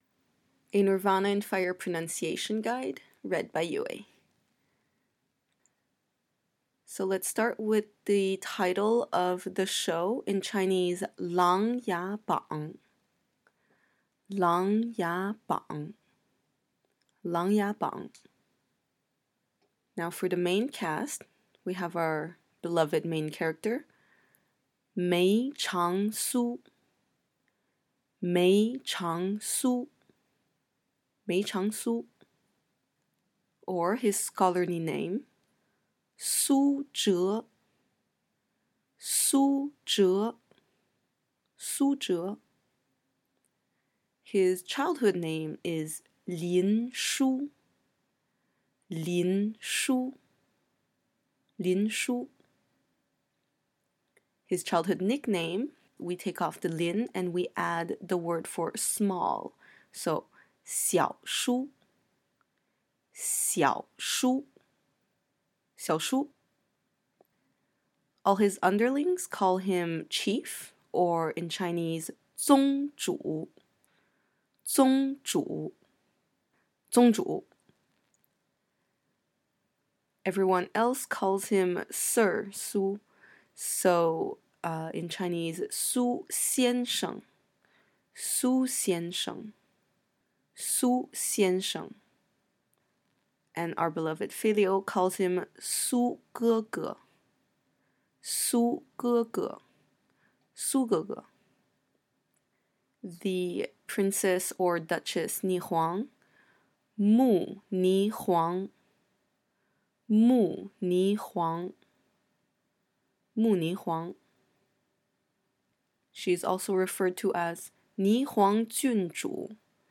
Nirvana in Fire pronunciation guide
I believe my accent is fairly Standard but feel free to disagree.